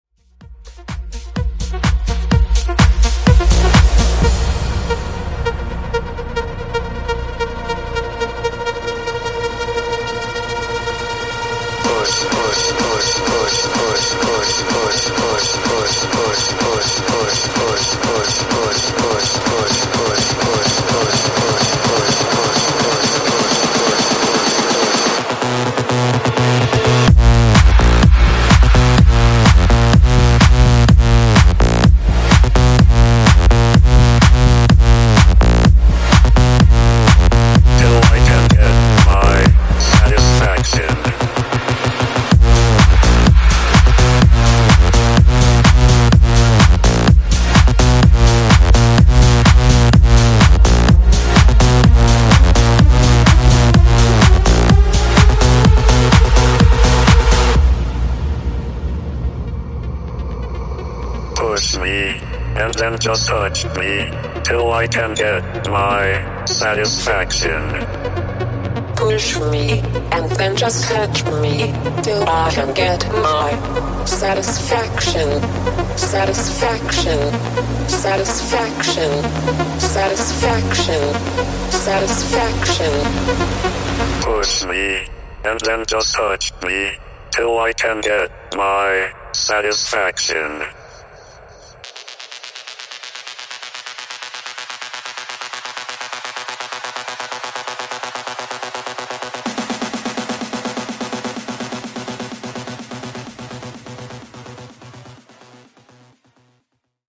Genres: DANCE , EDM , RE-DRUM
Clean BPM: 126 Time